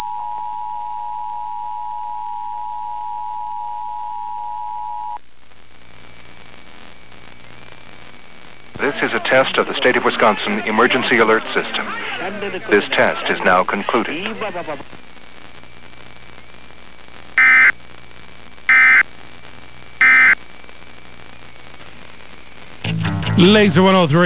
These are sound clips of some of my airchecks.
Areawide EAS Test From WLZR-102.9